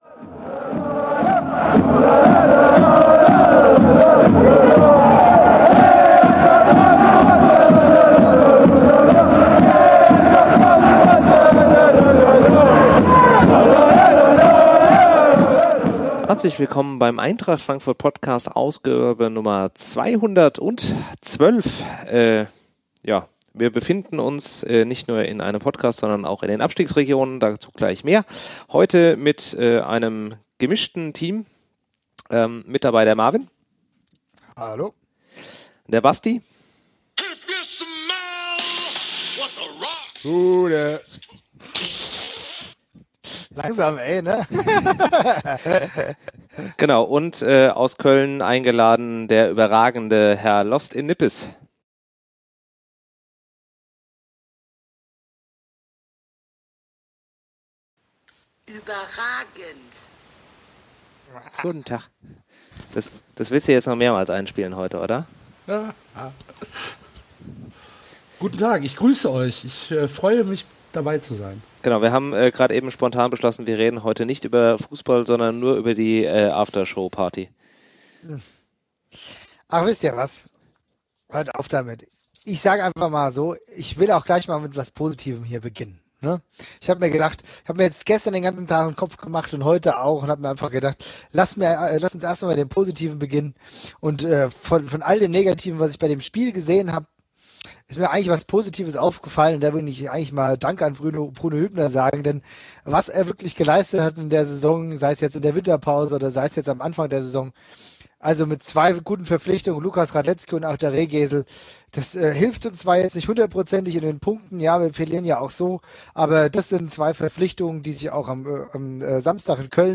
Die Stimmung ist stellenweise etwas getrübt, was zeigt, dass wir uns dann doch mitten im Abstiegskampf befinden.